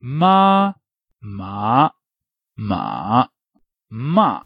Relative pitch changes of the four tones
The syllable "ma" pronounced with the four main tones
Zh-pinyin_tones_with_ma.ogg.mp3